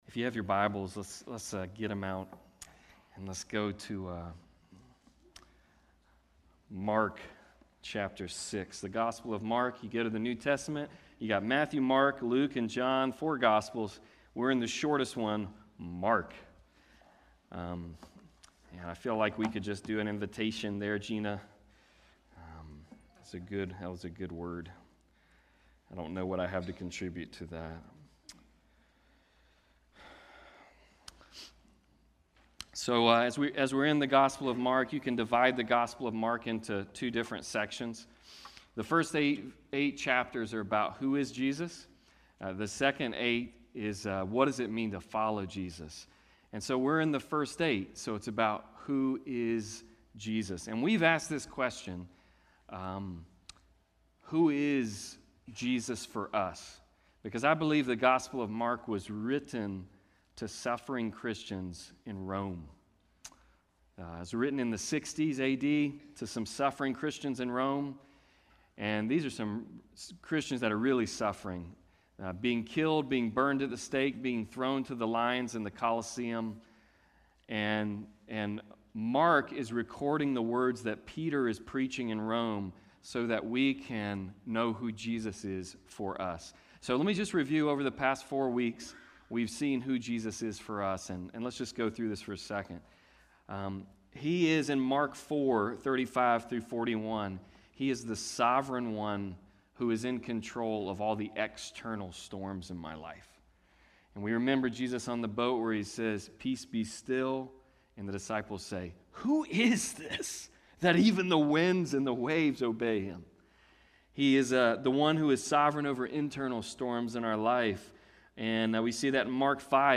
Passage: Mark 6:1-13 Service Type: Sunday Service